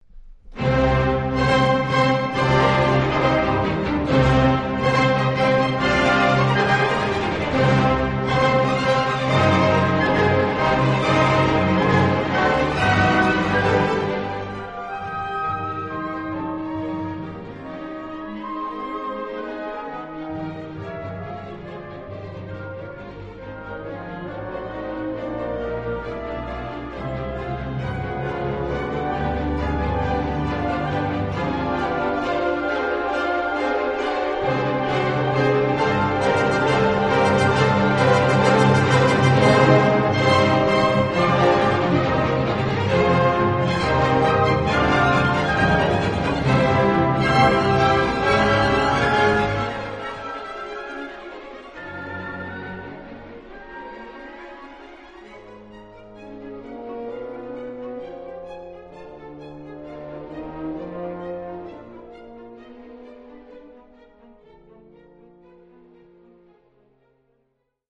Bewegt, feurig 14:00